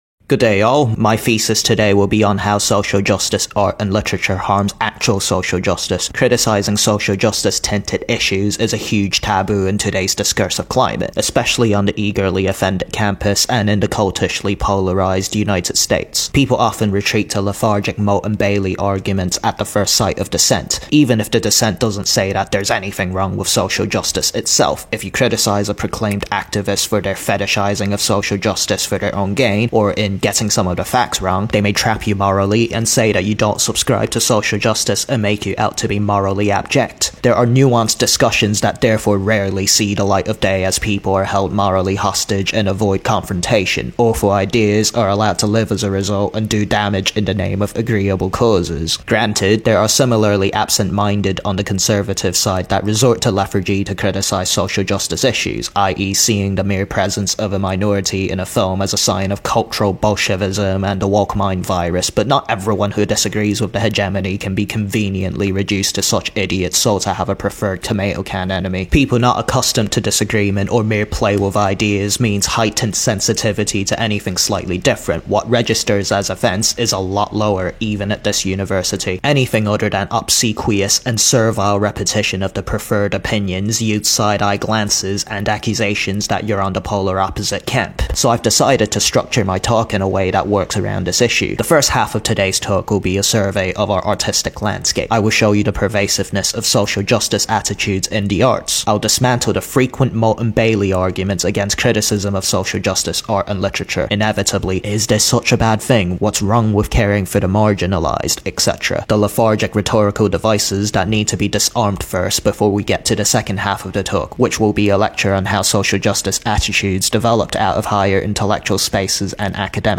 This video was adapted from a lecture I gave to some first year students at the Savannah C